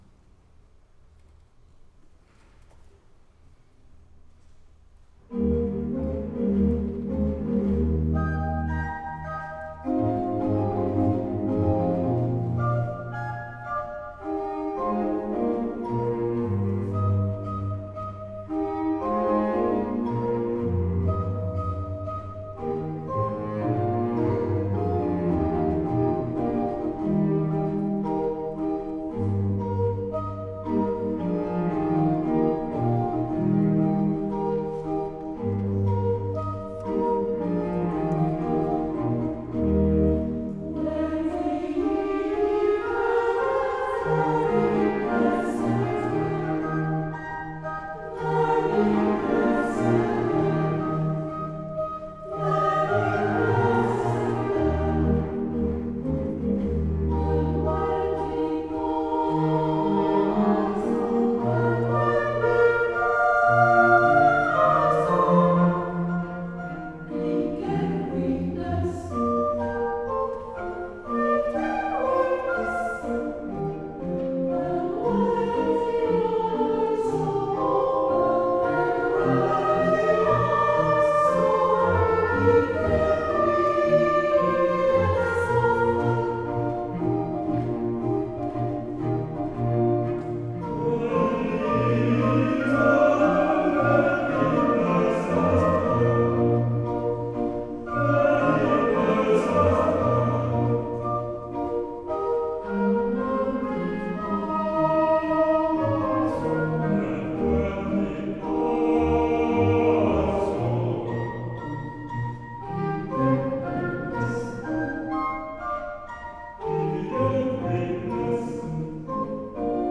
Ensemble Vocal
Ensemble Vocal Volubilis
Dès le début, cet ensemble à effectif volontairement réduit (environ 20 chanteurs) s’est attaché à travailler et proposer un répertoire essentiellement baroque, touchant à la fois au profane et au sacré, avec des incursions dans la musique de la Renaissance, mais aussi vers des œuvres plus tardives et contemporaines.
Depuis 2020, le chœur s’attache plus particulièrement à monter chaque année un programme composé de plusieurs pièces, susceptible d’être donné en représentation publique avec un petit effectif instrumental.
• Georg Friedrich Haendel en 2021 (« The Ways of Zion do Mourn »)